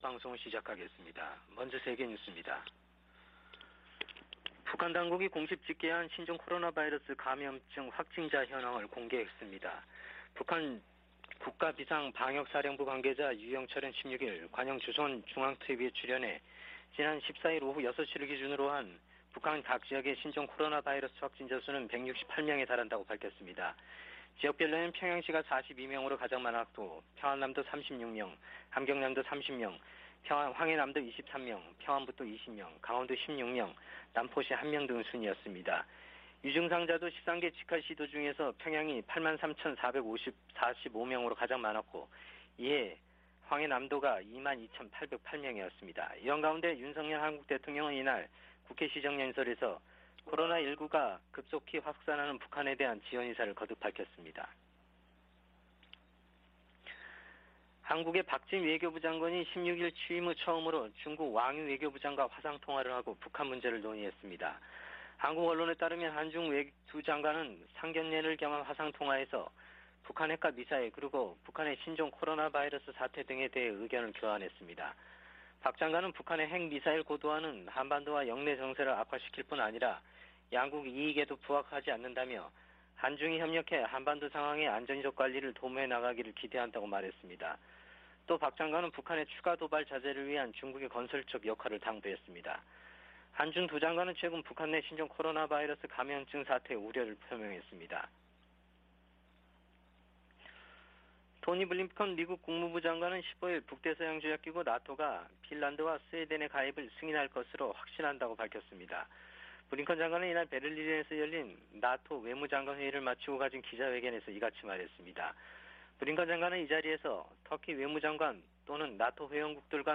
VOA 한국어 '출발 뉴스 쇼', 2022년 5월 17일 방송입니다. 북한에서 신종 코로나바이러스 감염병이 폭증한 가운데, 윤석열 한국 대통령은 백신 등 방역 지원을 아끼지 않겠다고 밝혔습니다. 미 국무부는 한국 정부의 대북 백신 지원 방침에 지지 입장을 밝히고, 북한이 국제사회와 협력해 긴급 백신 접종을 실시할 것을 촉구했습니다. 미국과 아세안은 특별정상회의에서 채택한 공동 비전성명에서 한반도의 완전한 비핵화 목표를 확인했습니다.